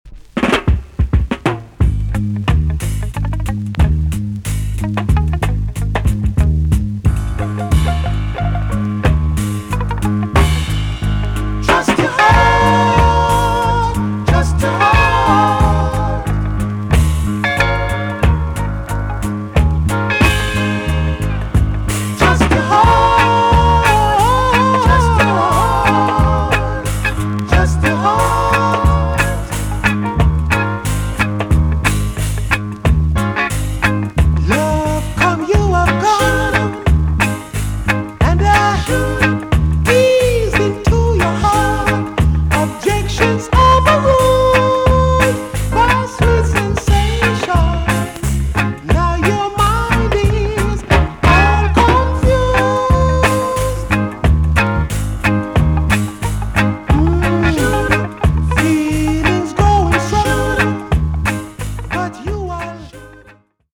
TOP >REGGAE & ROOTS
EX- 音はキレイです。
NICE SOUL COVER TUNE!!